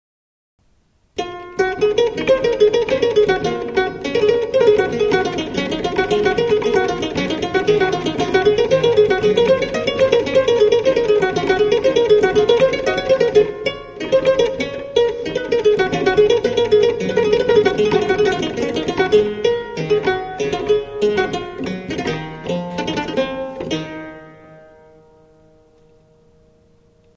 STRUMENTI a  TASTIERA
clavicordo.mp3